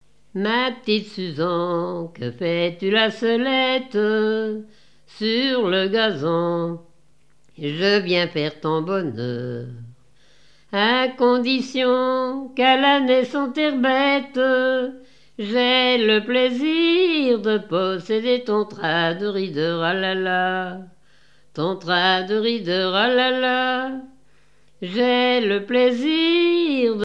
Mémoires et Patrimoines vivants - RaddO est une base de données d'archives iconographiques et sonores.
Genre strophique
Interprétation de chansons à partir d'un cahier de chansons
Pièce musicale inédite